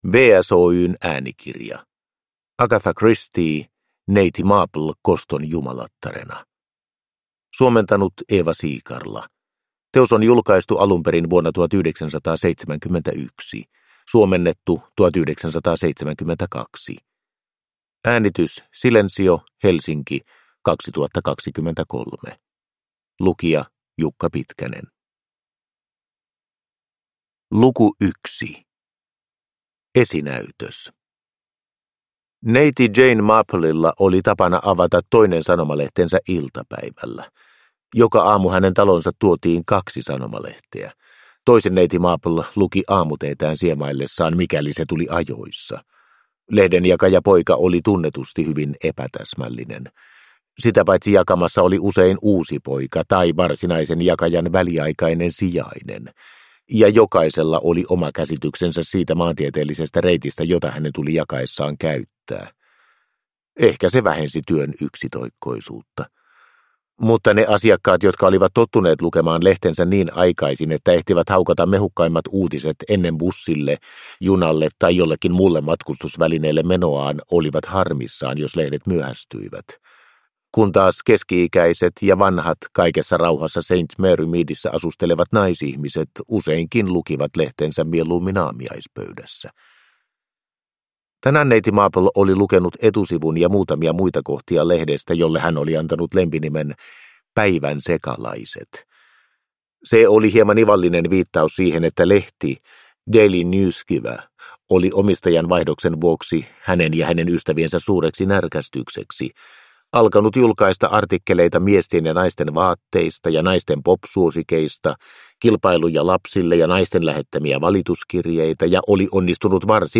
Neiti Marple koston jumalattarena – Ljudbok – Laddas ner